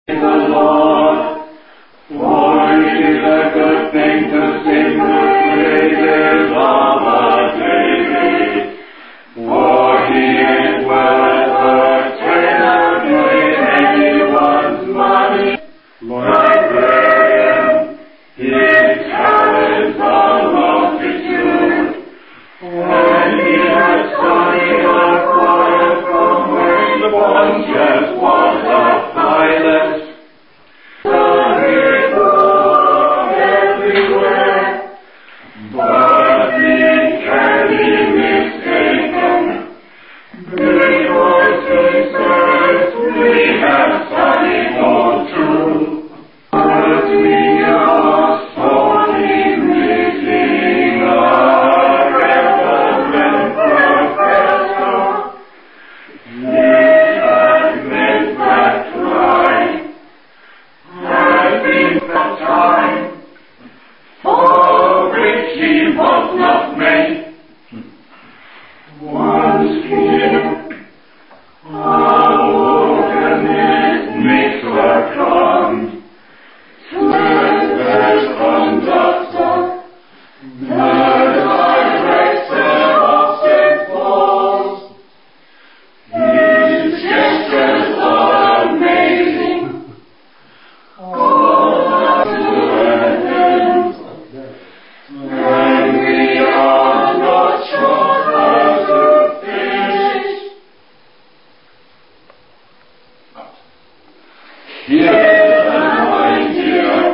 All Saints' Church Choir, Wokingham
Southwell Minster Choir Week, 2002
Singing the psalm